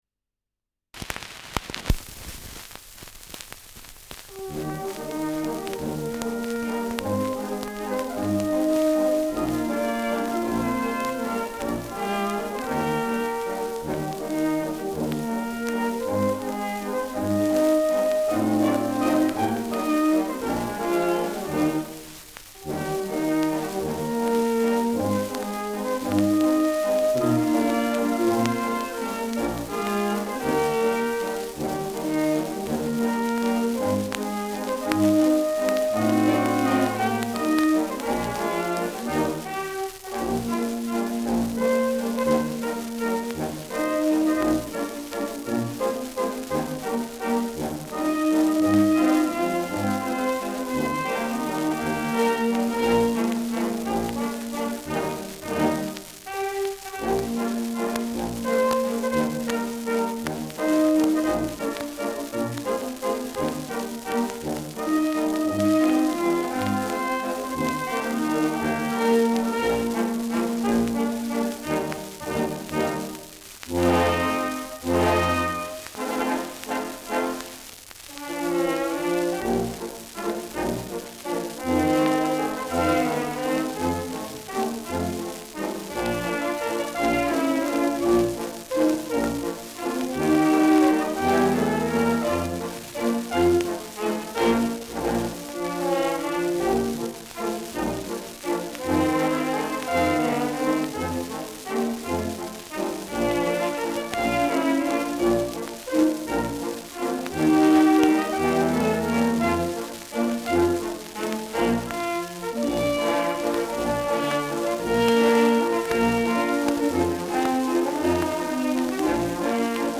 Schellackplatte
Stärkeres Grundrauschen : Gelegentlich leichtes Knacken
[München] (Aufnahmeort)